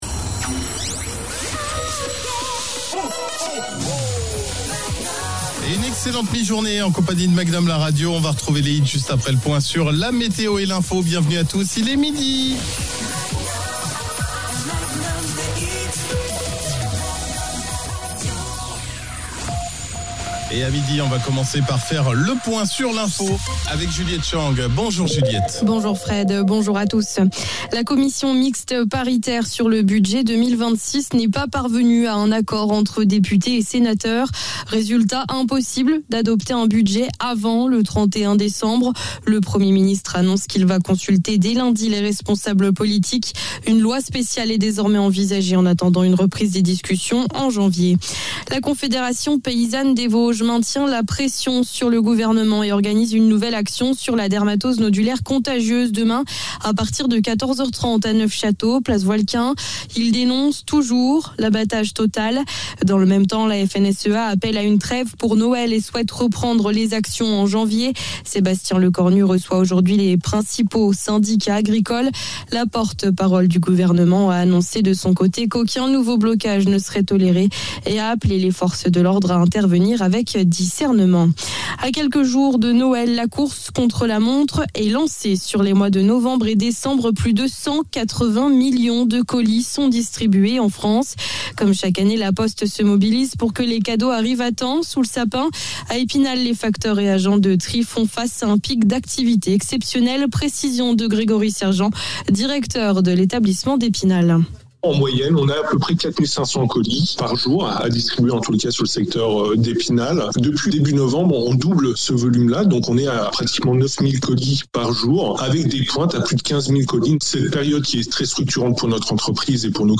19 décembre Retrouvez le flash de ce vendredi 18 décembre.